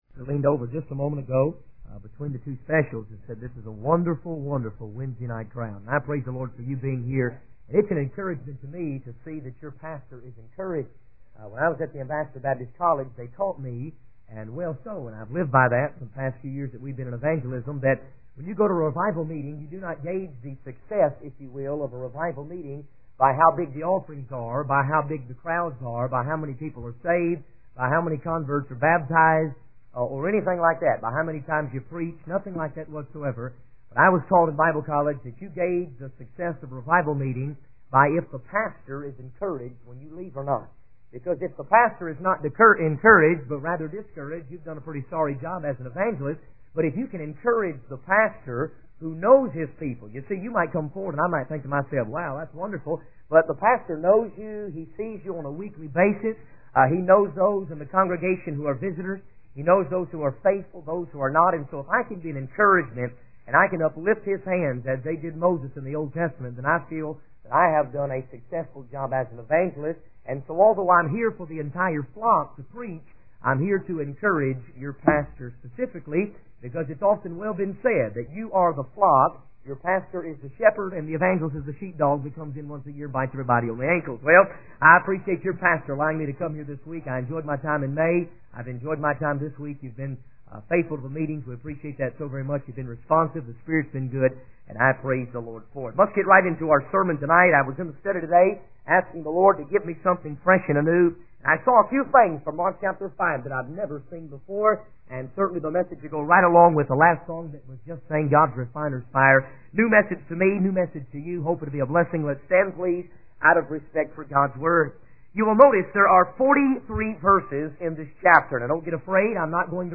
In this sermon, the preacher focuses on Mark chapter 5 and verse 1 as the text for the night. He highlights that in the 43 verses of this chapter, there are three hopeless situations that Jesus miraculously resolves.